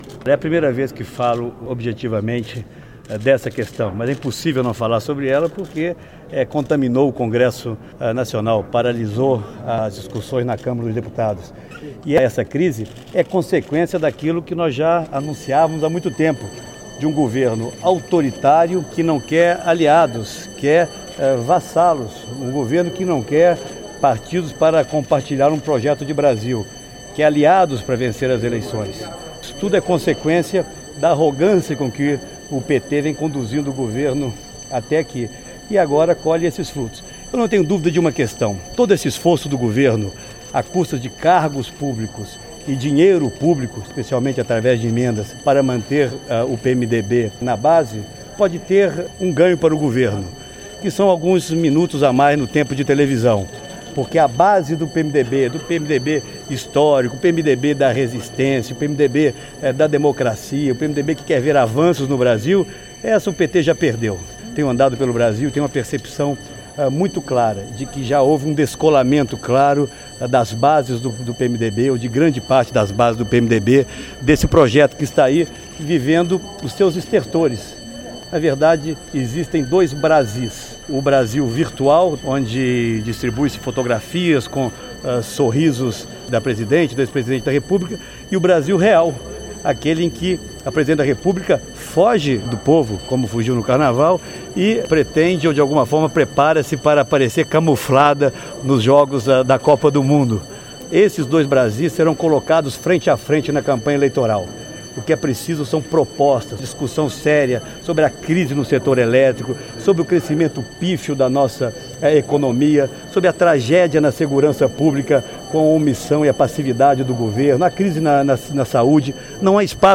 Segue trecho de entrevista do senador Aécio Neves em Brasília: